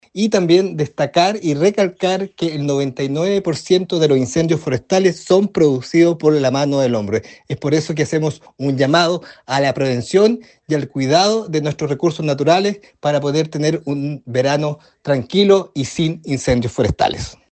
El evento, que se llevó a cabo en el frontis de la Municipalidad tuvo como objetivo, sensibilizar a los habitantes sobre la importancia de prevenir incendios forestales y fortalecer las medidas de seguridad en esta temporada de altas temperaturas y sequía.
Finalmente, el alcalde Carrillo instó a los vecinos a sumarse activamente a la iniciativa y recordó que cualquier emergencia puede ser reportada a través de los números de emergencia de Bomberos, Carabineros, CONAF o seguridad ciudadana de San Pablo.
26-diciembre-24-Marco-Carrillo-Emergencias.mp3